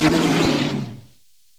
PixelPerfectionCE/assets/minecraft/sounds/mob/polarbear/hurt1.ogg at mc116